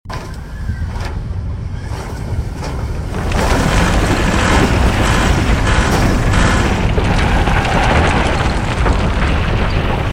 The Bus Fell Off The Sound Effects Free Download